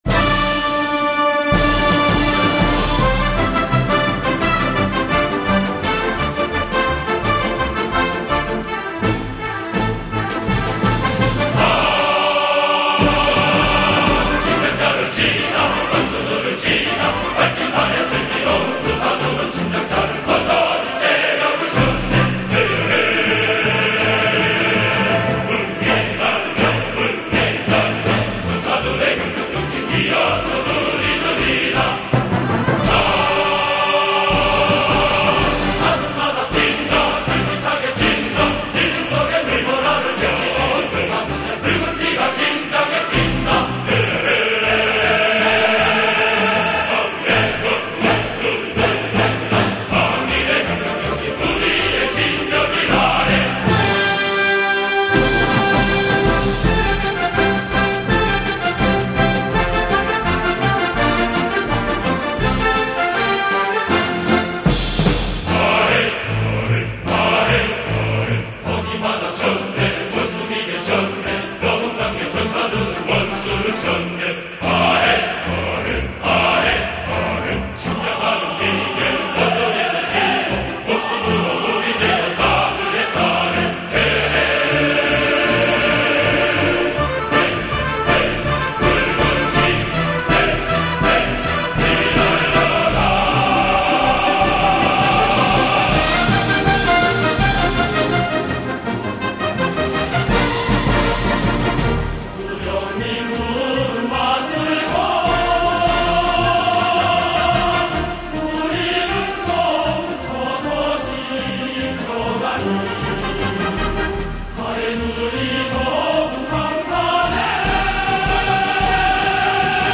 Народные песни